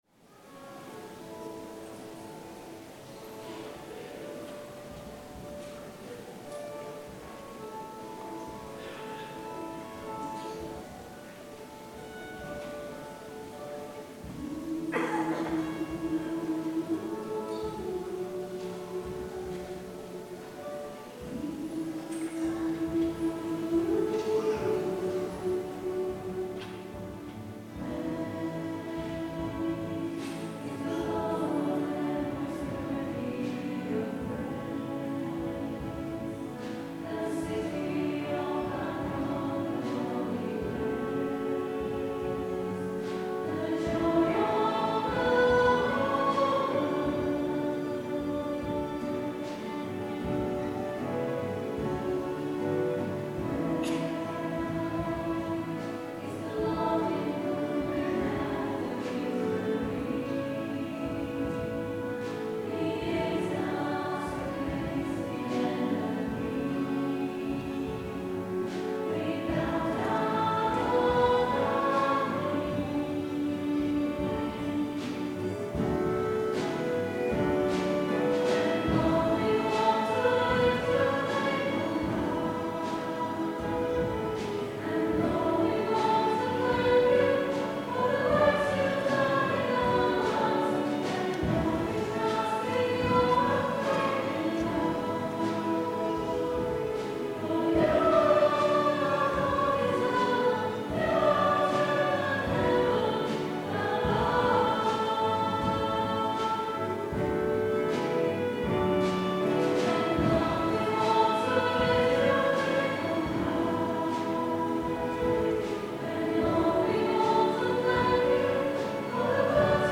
Recorded on Sony Minidisc in digital stereo at Easter Sunday mass at 10am on 23rd March 2008.